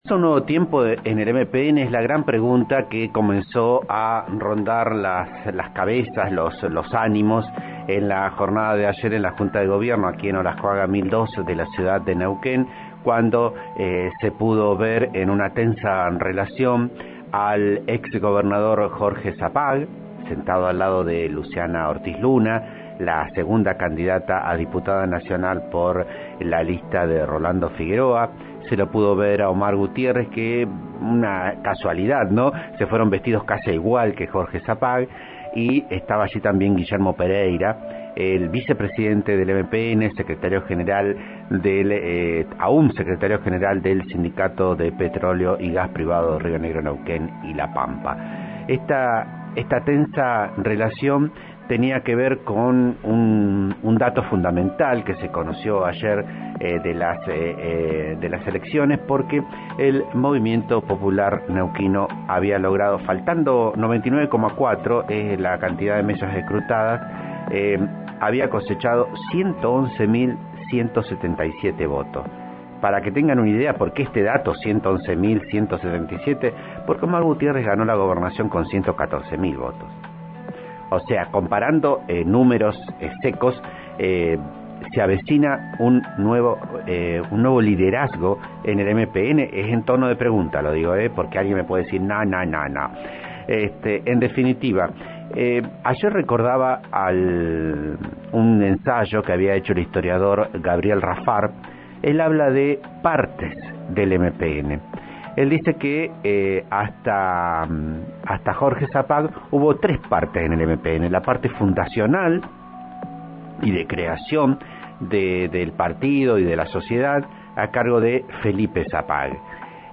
Análisis: La nueva parte que asoma en la mesa del poder provincial de Neuquén